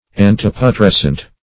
Antiputrescent \An`ti*pu*tres"cent\, a.